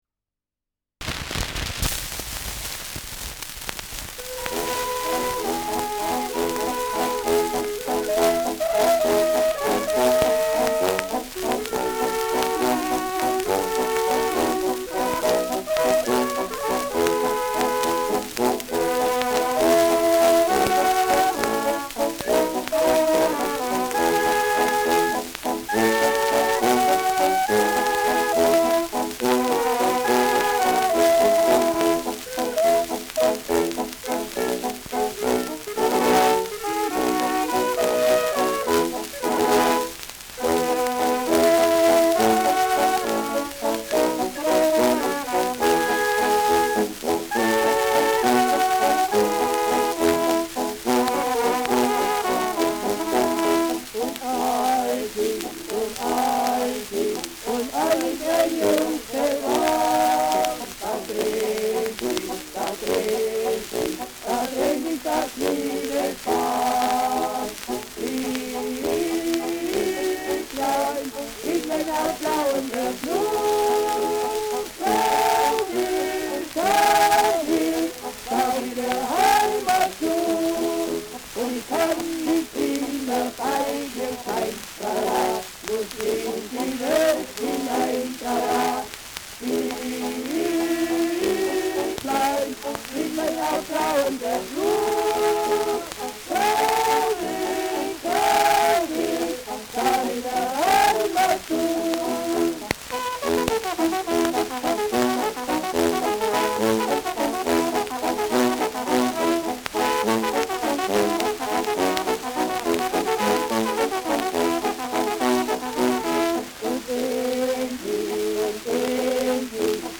Junggesellen-Walzer : mit Gesang
Schellackplatte
Starkes Grundrauschen : Gelegentlich leichtes bis stärkeres Knacken
Kapelle Die Alten, Alfeld (Interpretation)